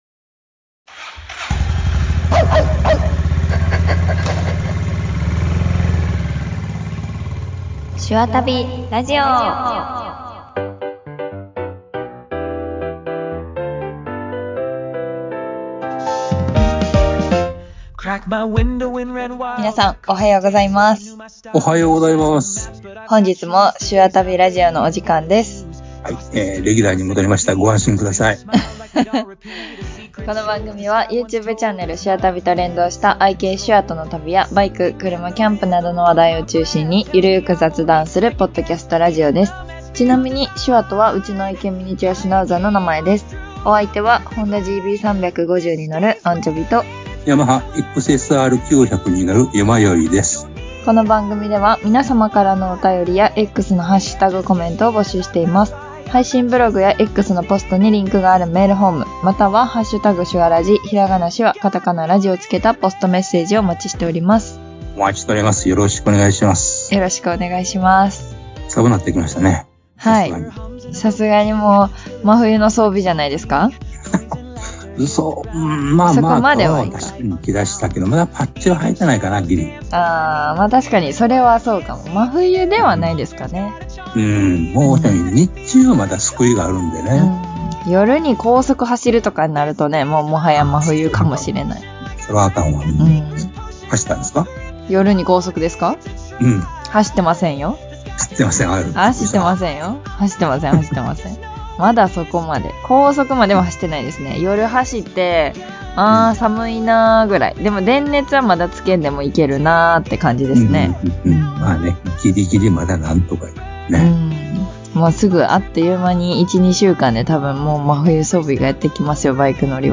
YouTube「しゅあタビCHANNEL」と連動した、 愛犬しゅあとの旅と、バイク、キャンプを中心に雑談する ポッドキャストラジオです。